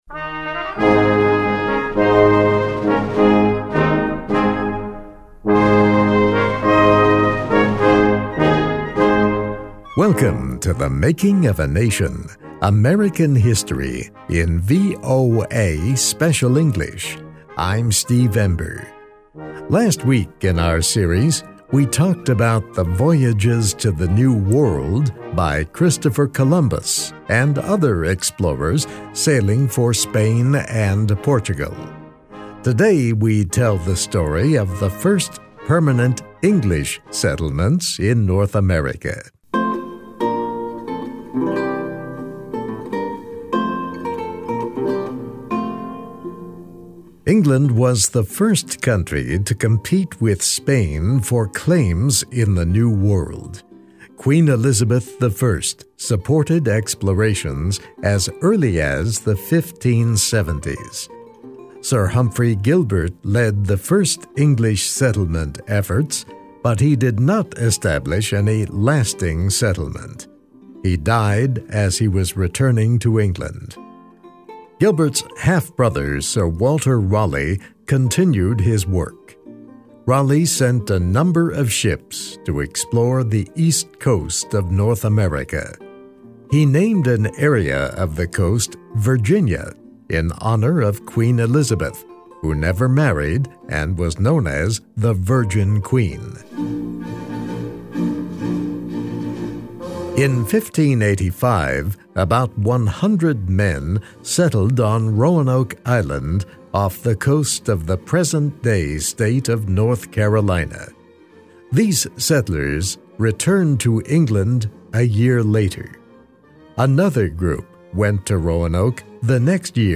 Read, listen and learn English with this story.